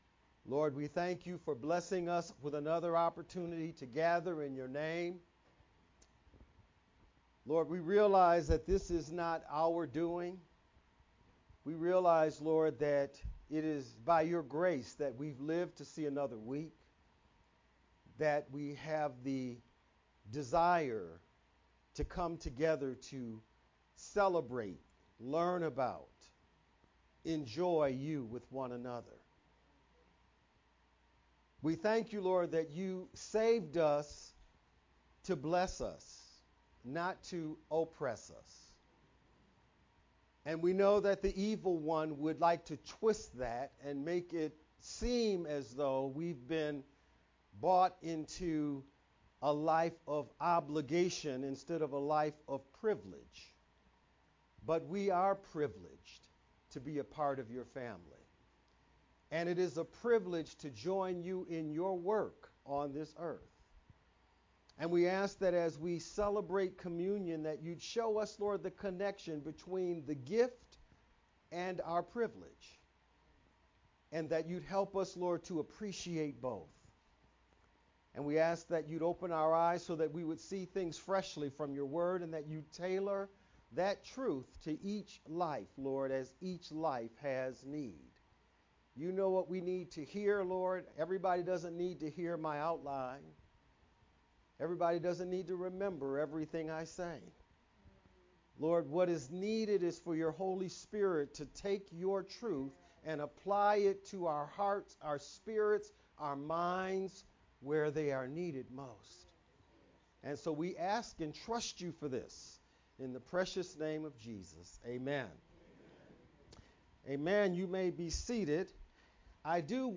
March-22-VBCC-Sermon-only-edited-CD.mp3